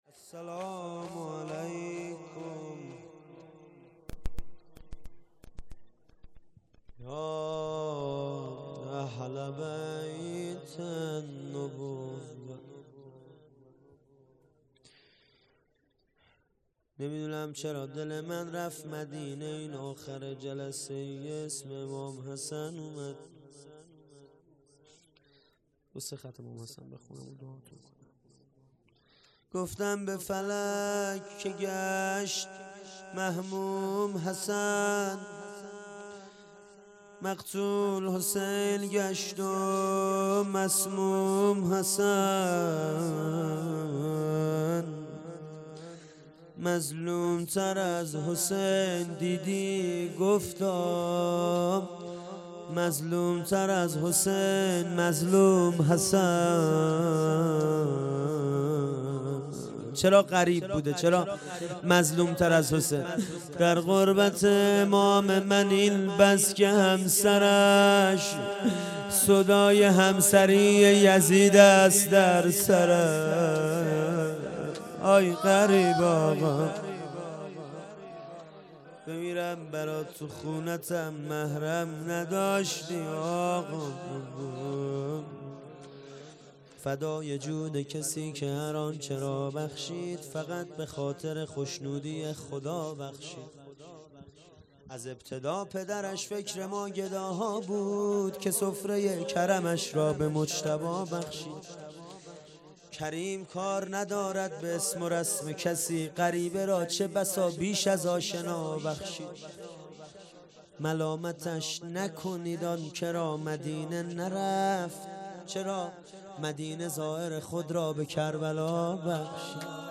السلام علیکم یا اهل بیت النبوه _ زمزمه و روضه
اقامه عزای شهادت امام رضا علیه السلام